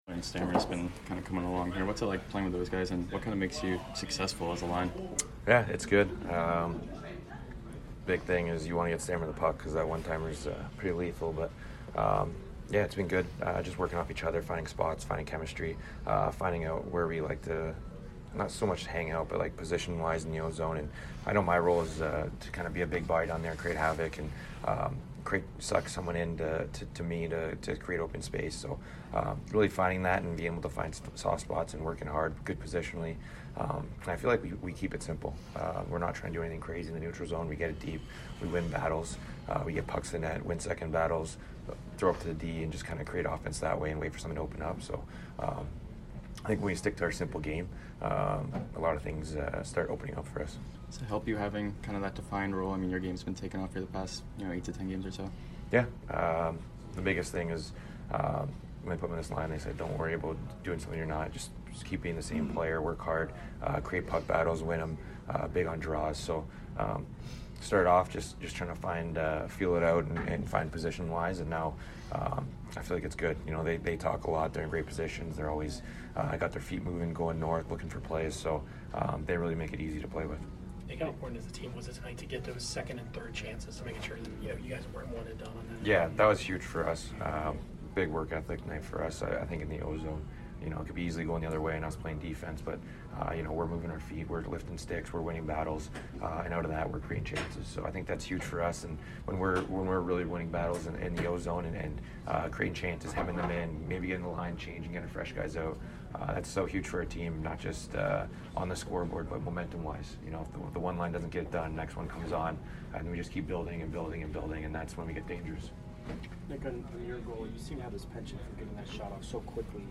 Nick Paul Post Game 11/15/22 vs DAL